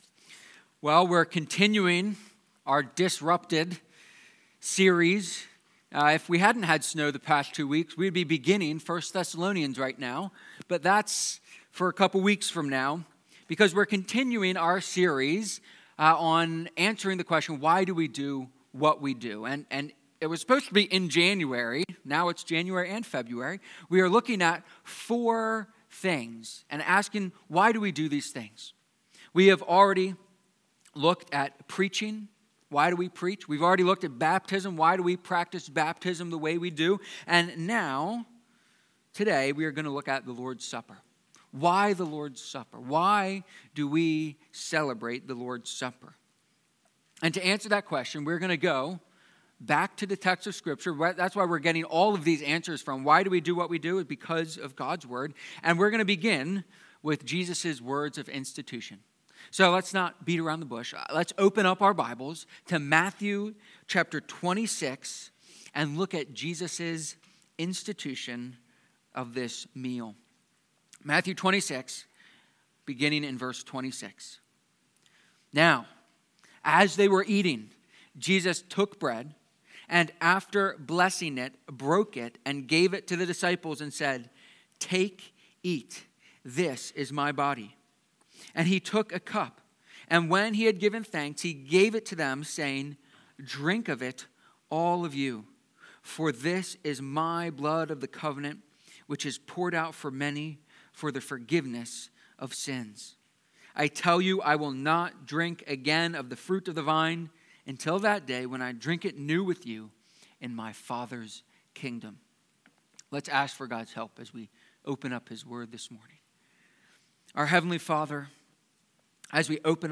lords-supper-sermon.mp3